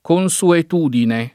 consuetudine [ kon S uet 2 dine ]